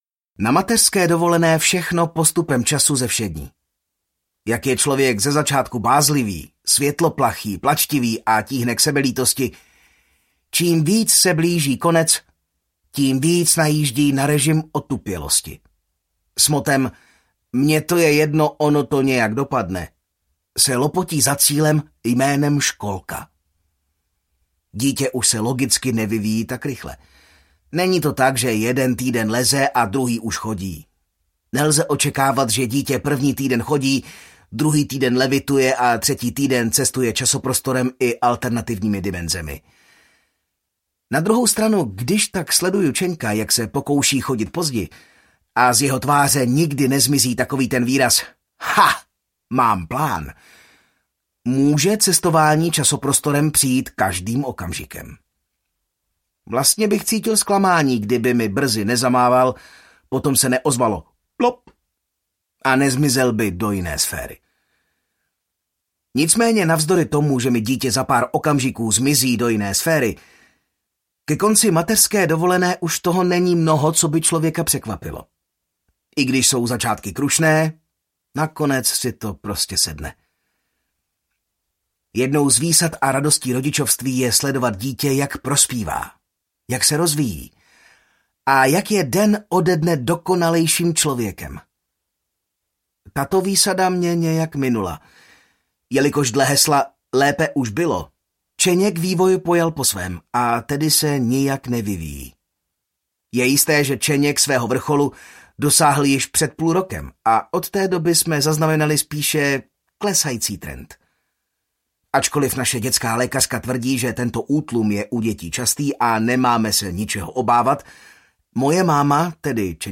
Deníček moderního fotra 3 aneb Historky z rodičovského podsvětí audiokniha
Ukázka z knihy